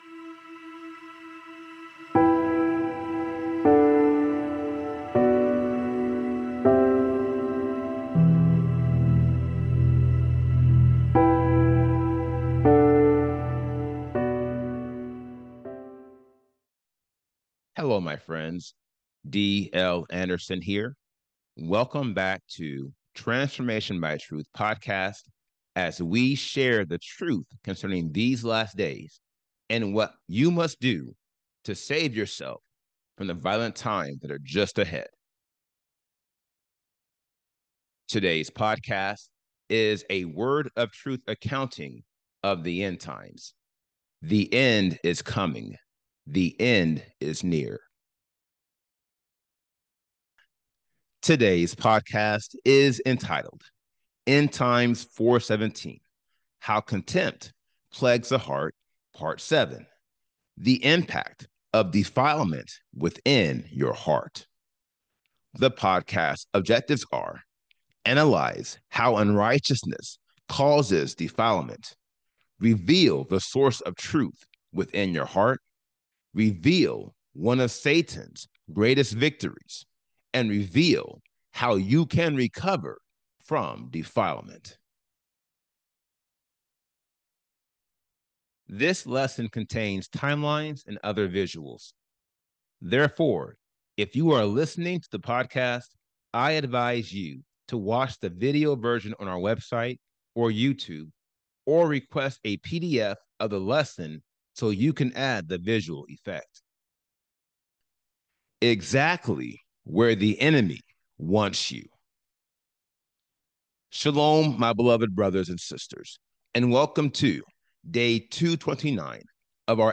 This podcast is a 400-level lecture dedicated to analyzing the 80 degrees of lawlessness and showing you how you can eliminate each one to obtain the Seal of Elohim. Its purpose is to analyze how unrighteousness causes defilement, reveal the source of Truth within your heart, reveal 1 of Satan’s greatest victories, and reveal how you can recover from defilement.